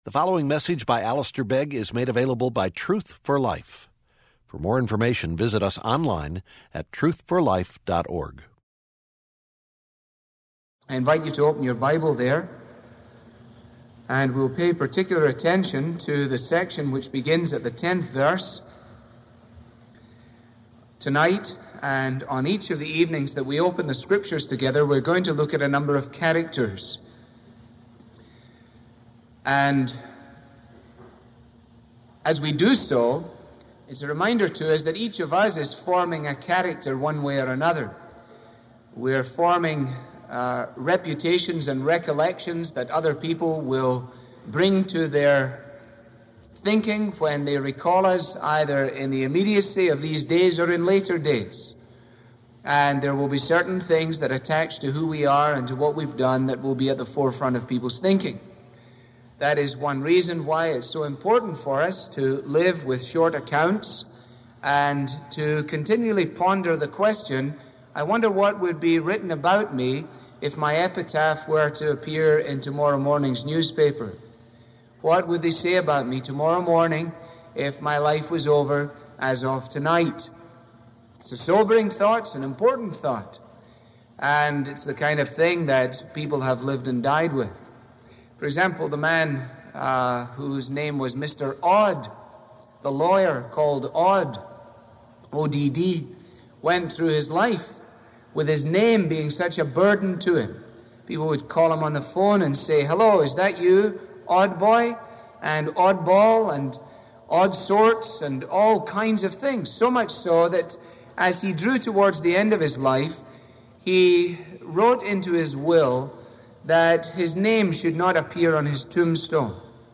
Sermons on service from Acts with a transcript from Truth For Life